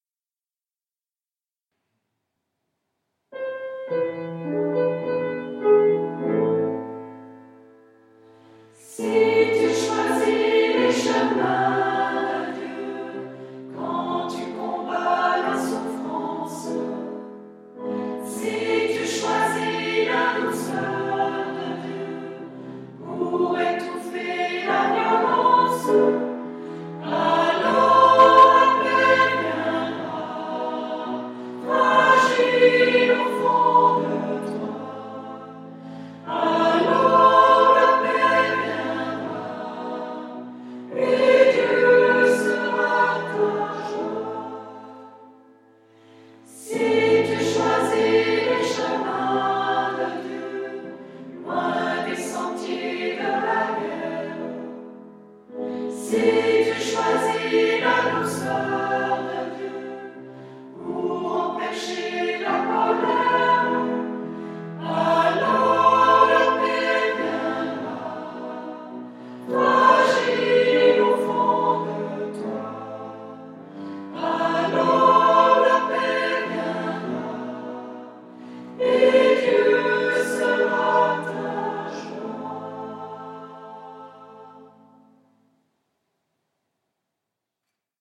Voici un chant d'assemblée à découvrir, qui invite à choisir les chemins de Dieu dans nos attitudes face à la souffrance, au mal ou à la violence. Avec la promesse de la paix de Dieu. Un chant plein d'espérance.